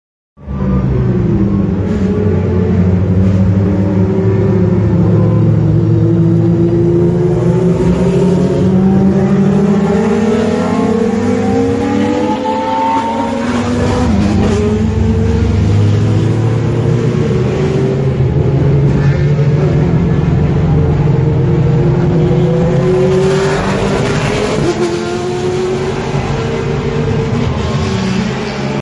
Download Racing sound effect for free.
Racing